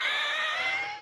ZomBunny Death.wav